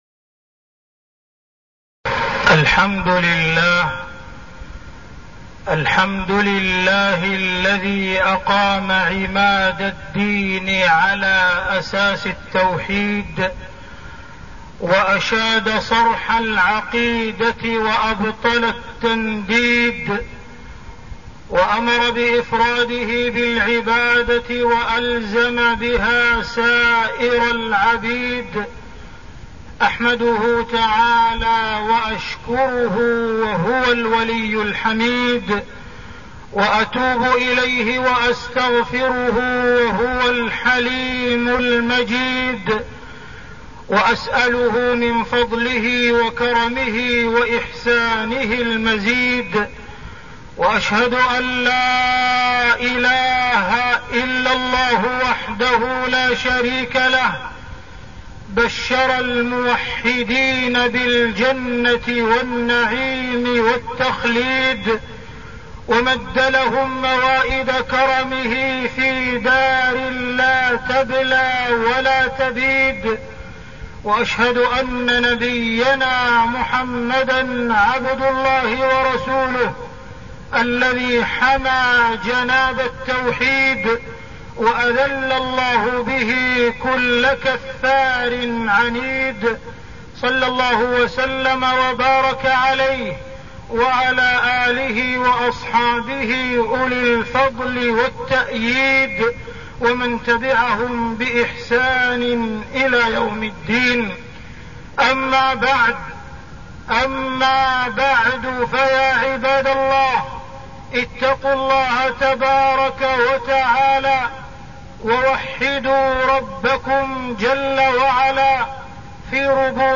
تاريخ النشر ١٦ صفر ١٤١٣ هـ المكان: المسجد الحرام الشيخ: معالي الشيخ أ.د. عبدالرحمن بن عبدالعزيز السديس معالي الشيخ أ.د. عبدالرحمن بن عبدالعزيز السديس إفراد الله بالعبادة